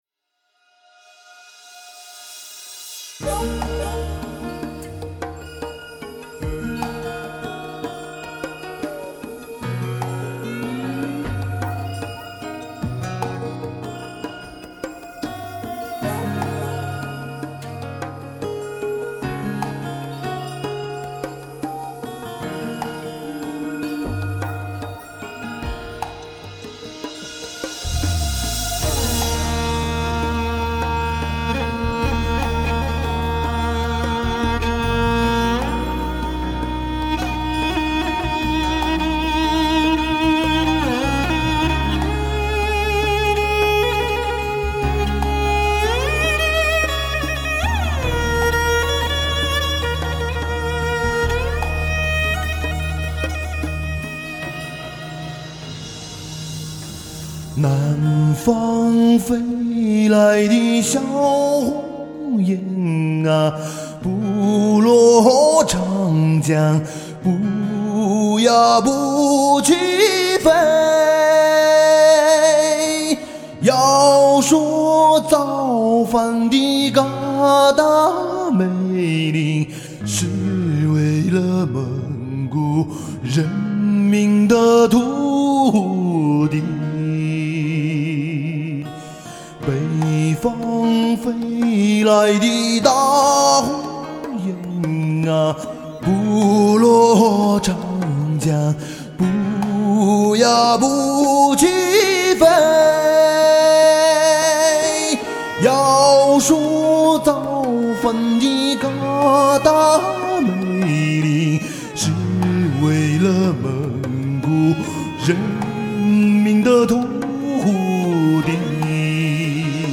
来自一个神奇高原最美的呼唤 独具藏族神韵的嗓音苍劲深情
内蒙民歌
马头琴配合长调勾画草莽辽原的景象，似一幅泼墨山水，
英雄主义悲剧的味道悄然浸出。